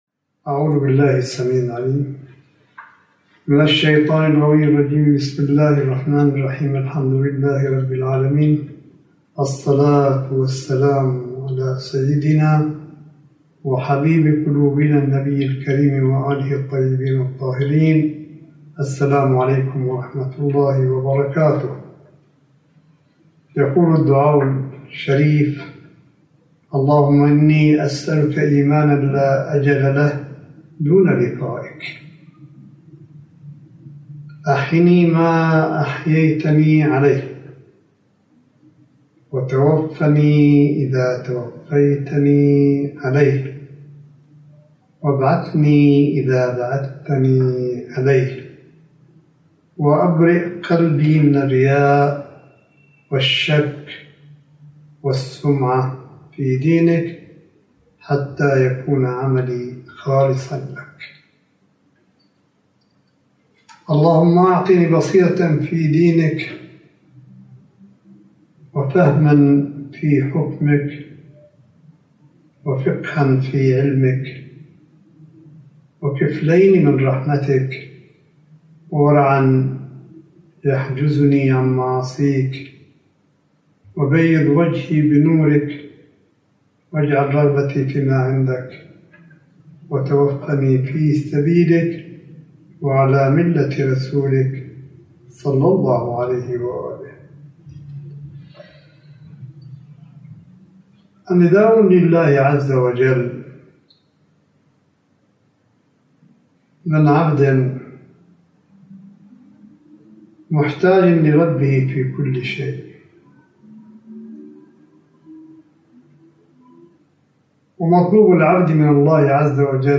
ملف صوتي للحديث الرمضاني (24) لسماحة آية الله الشيخ عيسى أحمد قاسم حفظه الله – 25 شهر رمضان 1442 هـ / 07 مايو 2021م